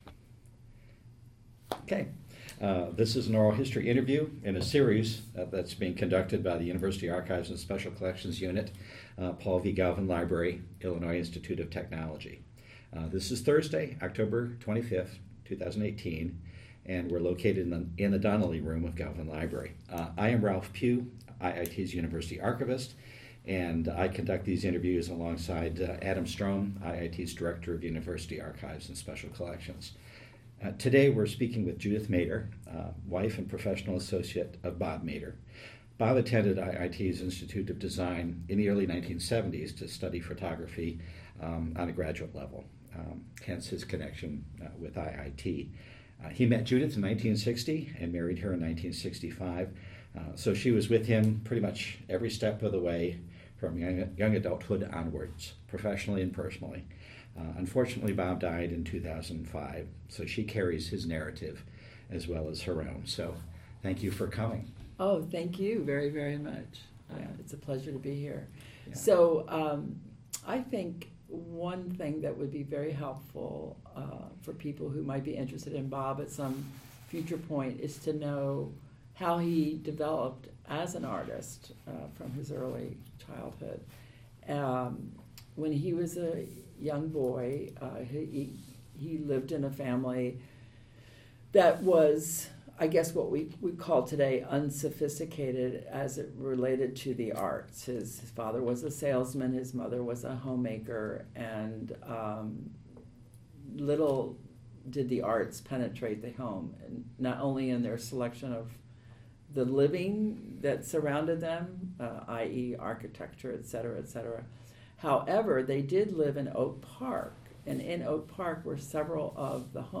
Type Interview File type audio/wav Subject Illinois Institute of Technology.